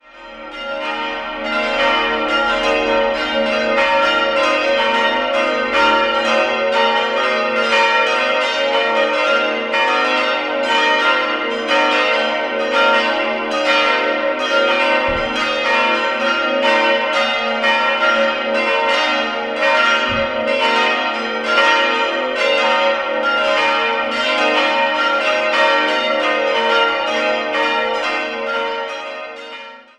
Schwandorf, Pfarrkirche St. Paul Die Pfarrkirche St. Paul liegt in der Weinbergsiedlung über der Stadt Schwandorf. Sie wurde, wie das gesamte Kirchenzentrum, Anfang der 1960er-Jahre errichtet und 1964 eingeweiht. 5-stimmiges Geläut: b'-c''-es''-f''-g'' Die Glocken wurden 1964 von der Gießerei Hofweber in Regensburg gegossen.